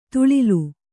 ♪ tuḷilu